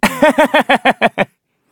Kibera-Vox_Happy3_kr.wav